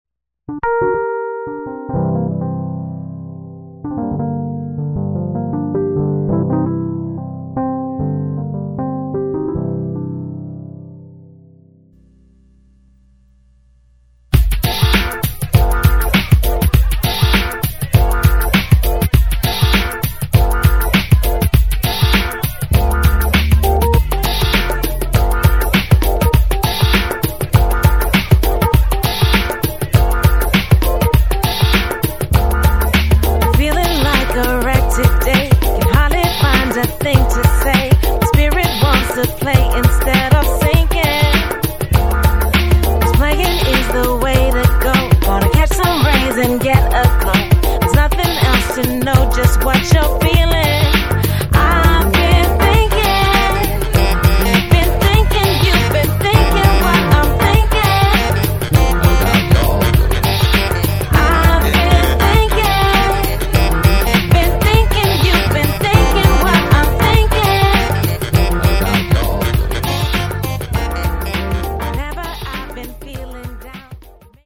with guest singers